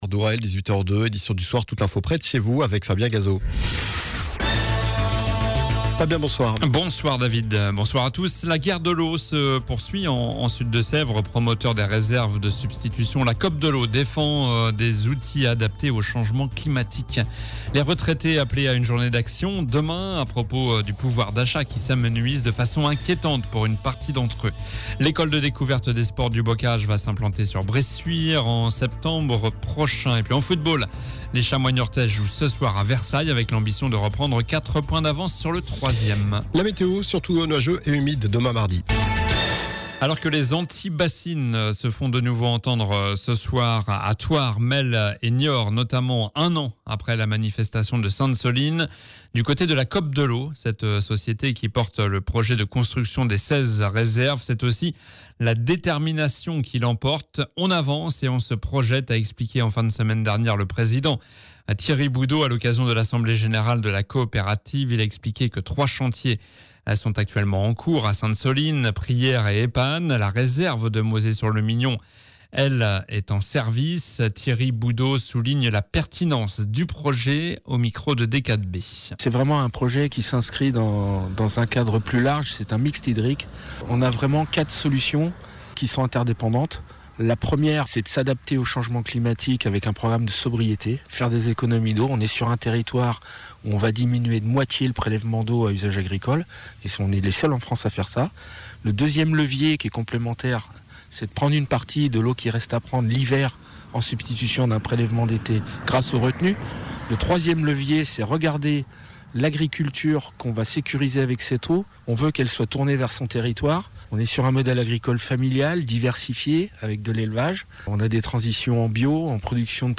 Journal du lundi 25 mars (soir)